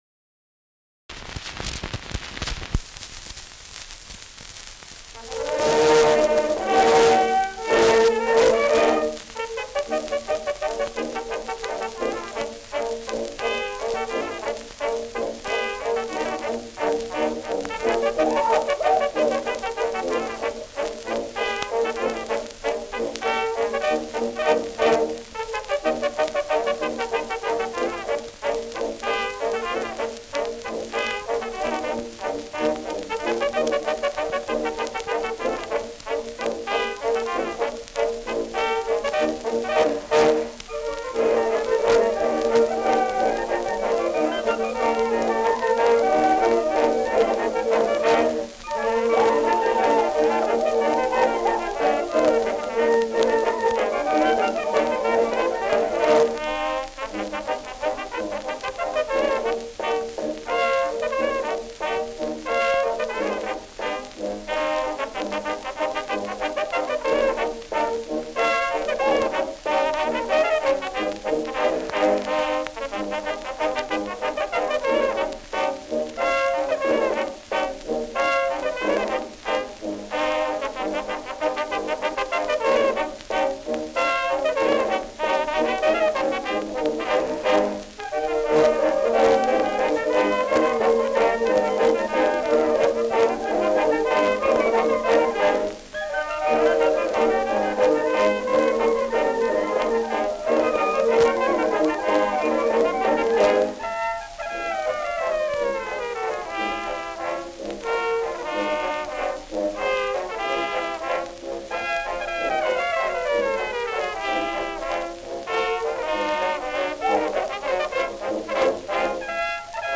Schellackplatte
Stärkeres Grundrauschen : Gelegentlich leichtes bis stärkeres Knacken : Verzerrt an lauteren Stellen
Die durchgehend zweistimmig angelegten Trompetenteile sind vermutlich ausnotiert, im Unterschied dazu scheinen die Zwischenspiele, in denen verschiedene Melodien „gegeneinander“ klingen, frei improvisiert.